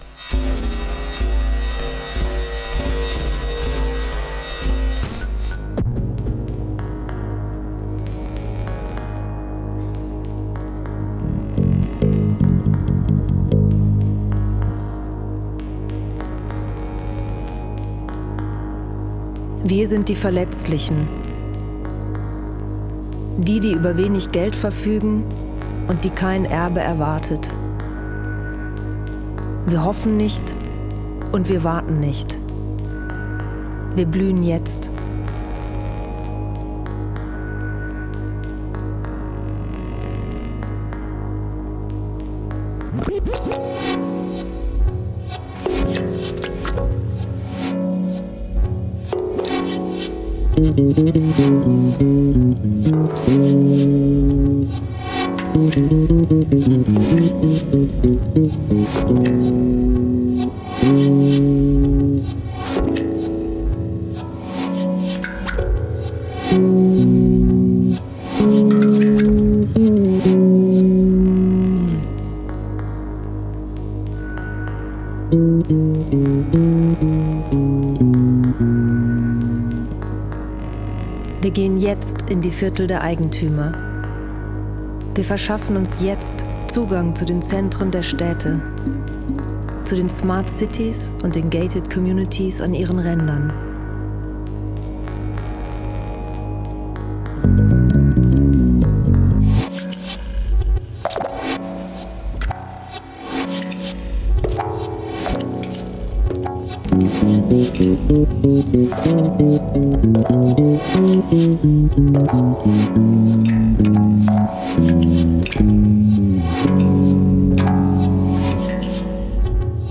Die deutsche Variante habe ich selbst in meinem Studio in Düsseldorf eingesprochen und in Zusammenarbeit mit meinem Mann
um eine rhythmische Sequenz und Bassläufe erweitert: